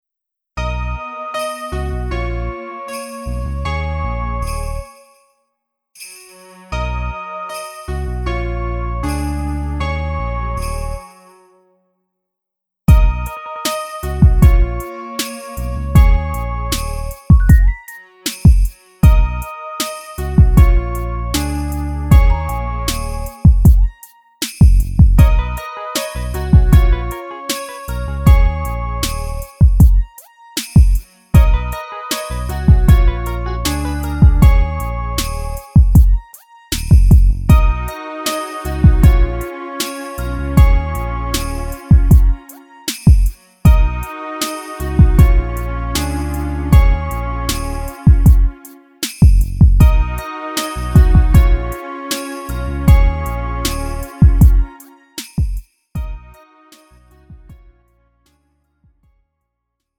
음정 -1키 3:11
장르 가요 구분 Lite MR